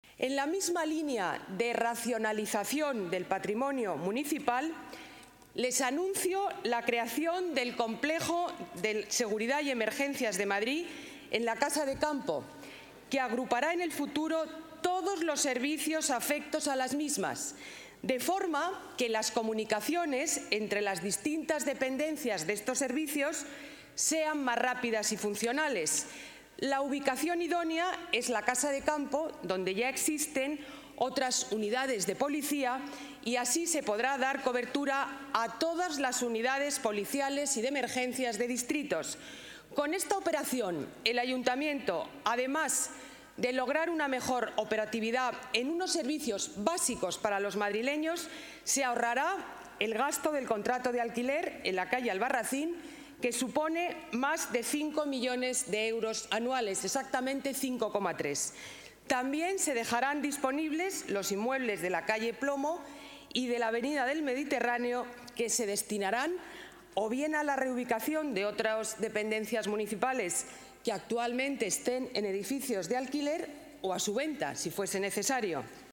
Nueva ventana:Declaraciones de la alcaldesa de la Ciudad de Madrid, Ana Botella: Ciudad Seguridad